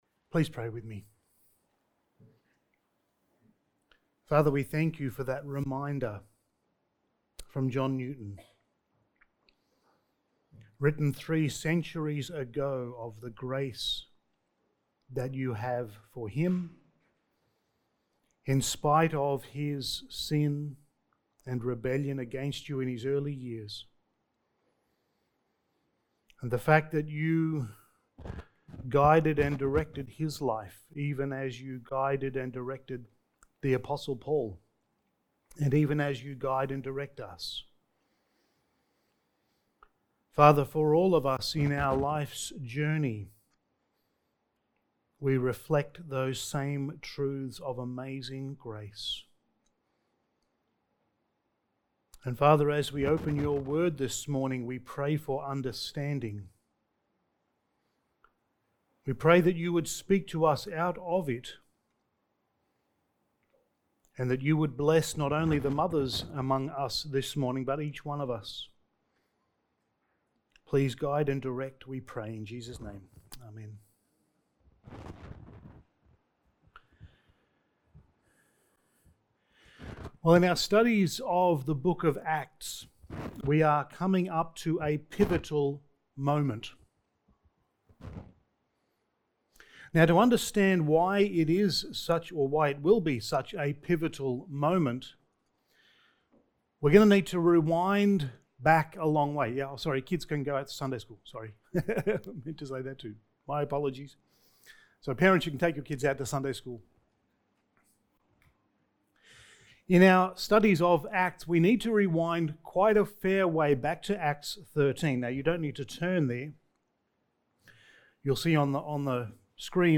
Passage: Acts 21:1-16 Service Type: Sunday Morning